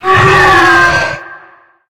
main Divergent / mods / Soundscape Overhaul / gamedata / sounds / monsters / bloodsucker / die_3.ogg 16 KiB (Stored with Git LFS) Raw Permalink History
die_3.ogg